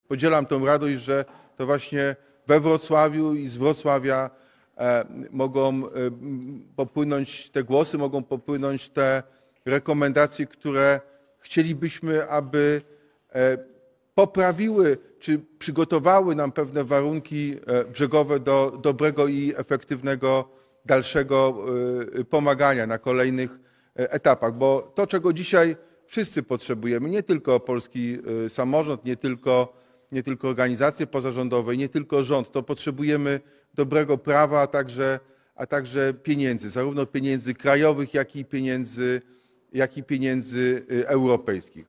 – Zakończyliśmy obrady Okrągłego Stołu, w którym wzięli udział przedstawiciele rządu, polskiego samorządu, organizacji pracodawców, organizacji związkowych, środowisko akademickie i sektor organizacji pozarządowych – mówił Jacek Sutryk – prezydent Wrocławia.
Obradom Samorządowego Okrągłego Stołu przysłuchiwało się kilkaset osób, które zasiadły na widowni we wrocławskiej Hali Stulecia.